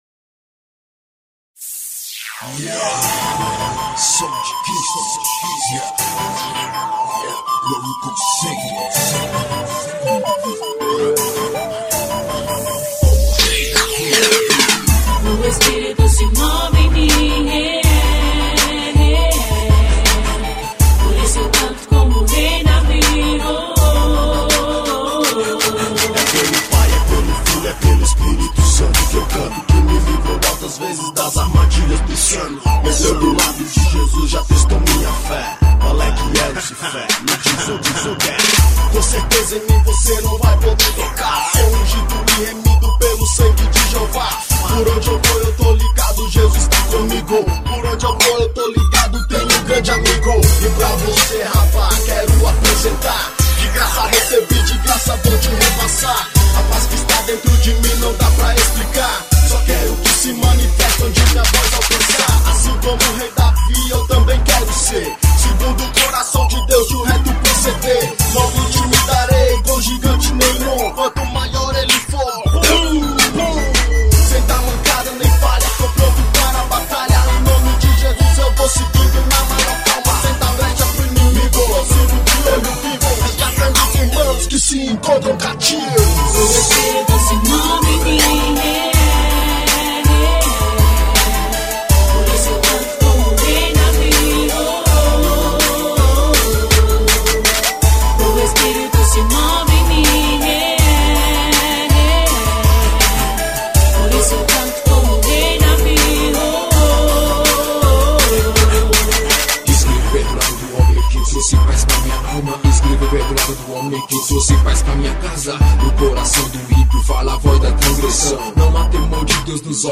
rap gospel.